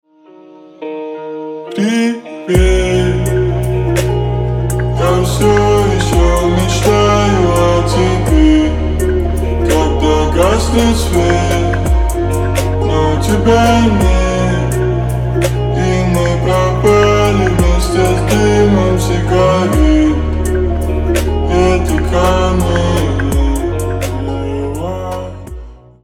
• Качество: 256, Stereo
гитара
медленные
Chill Trap
Mashup